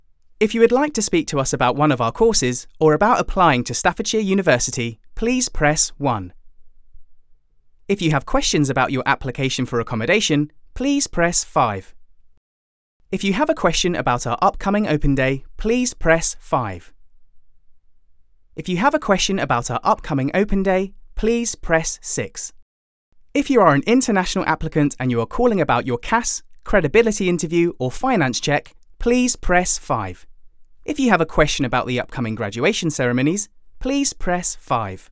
Inglés (Británico)
Comercial, Joven, Natural, Travieso, Amable
Telefonía